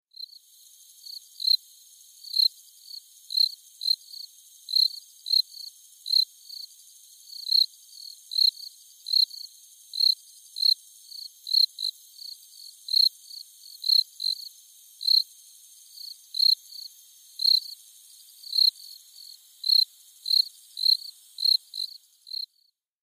Звуки стрекотания
Сверчок в природе создает звук